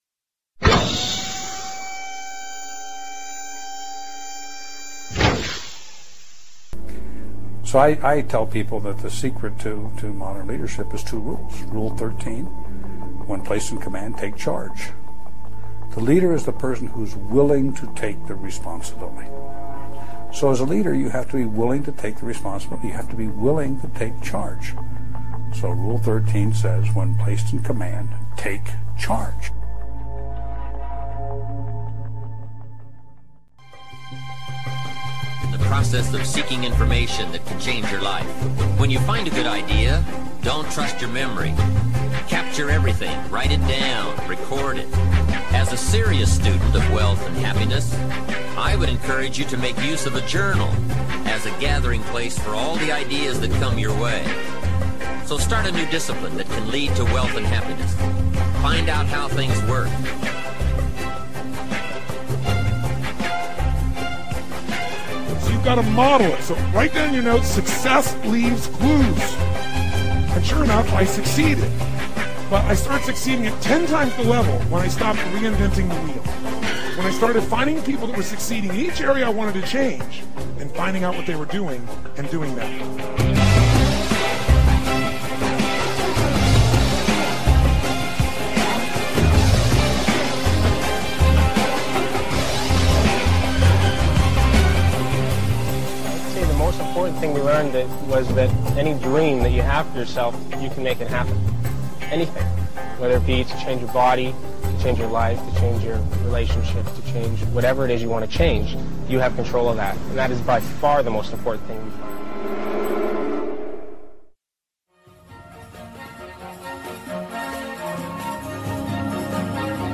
Many of the sounds, music and speech are mixed to create and associate feelings to certain things and activities.